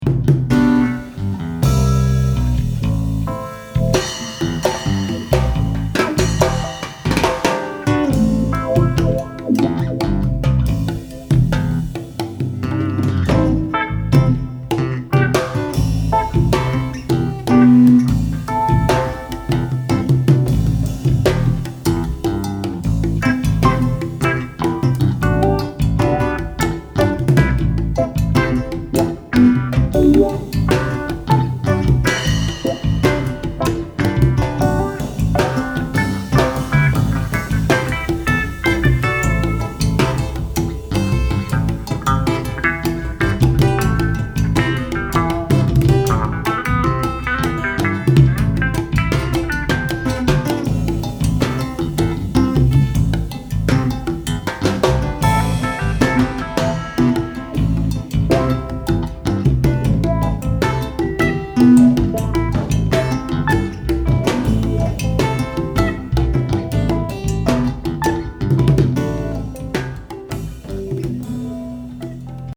^^^^^^^^^Nimbus1_(Live Trio living room one mic)